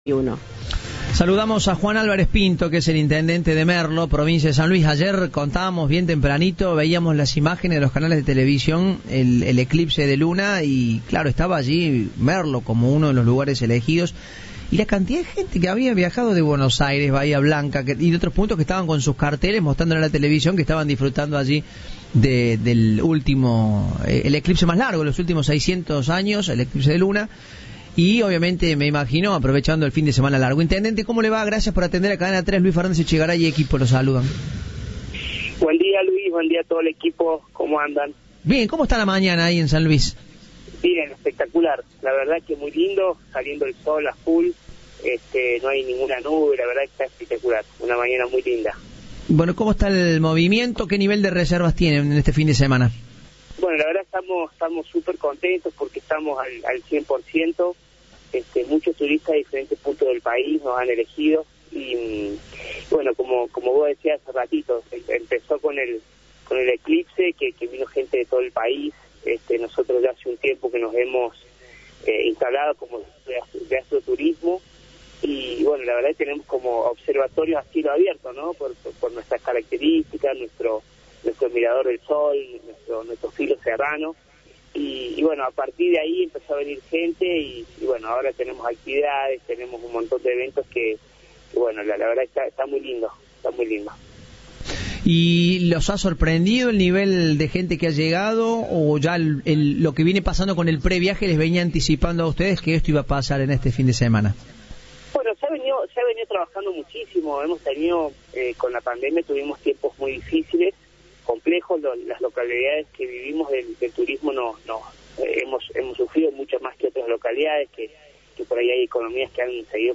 En San Luis, el intendente de Merlo, destacó en diálogo con Cadena 3 que son uno de los destinos más elegidos por el turismo.
Entrevista de "Informados, al regreso".